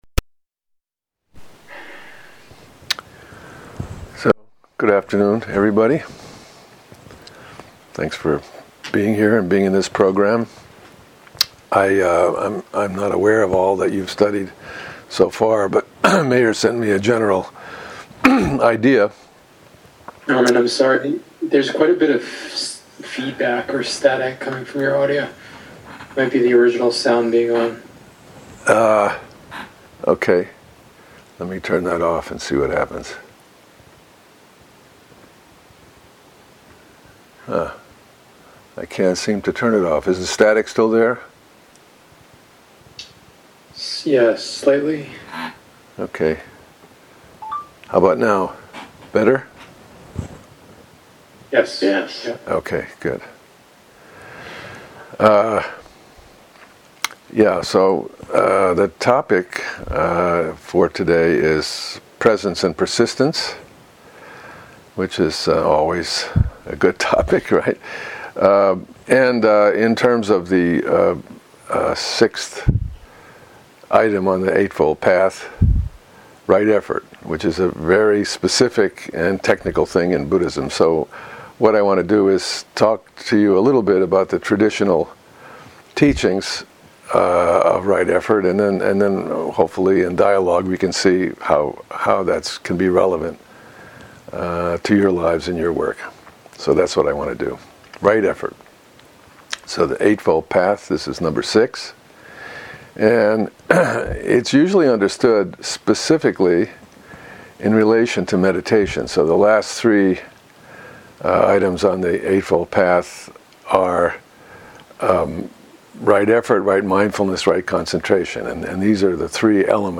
dharma talk